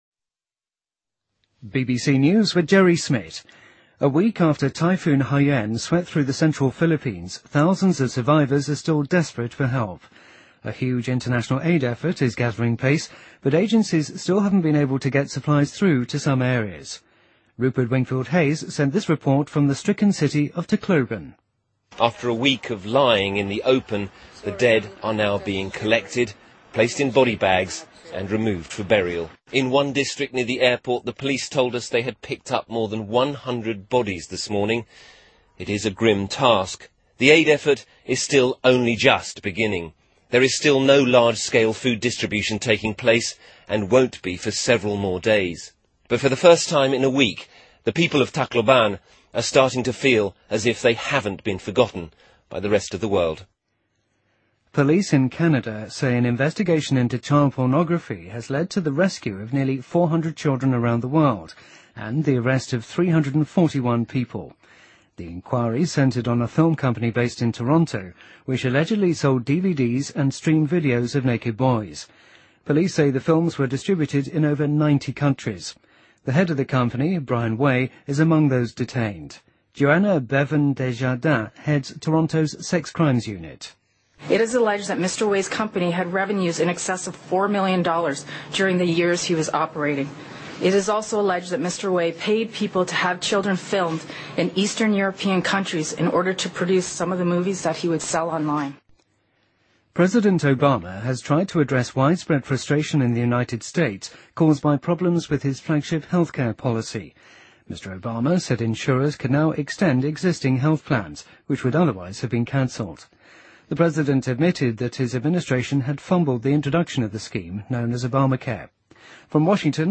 BBC news,美国法官驳回了作家们试图阻止谷歌扫描数百本书并在网上提供其摘要的诉讼